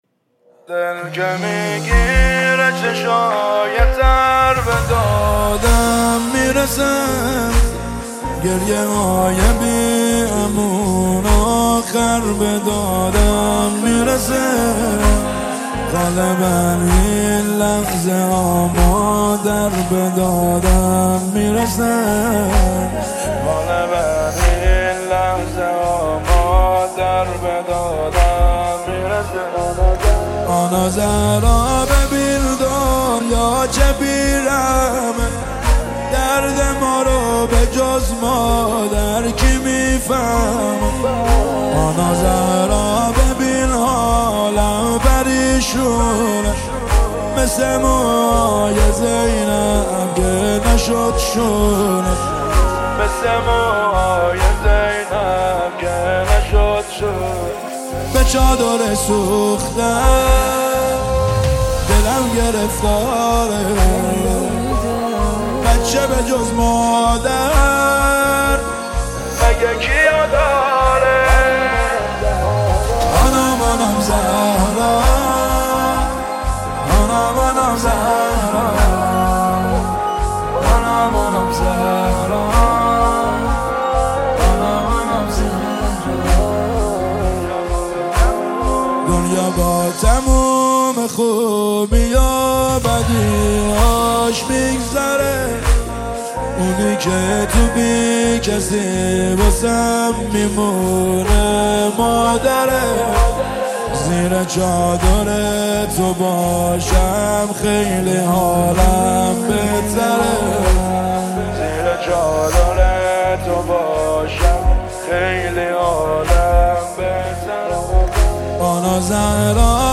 نماهنگ مذهبی